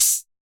Boomin - Cymbal 2.wav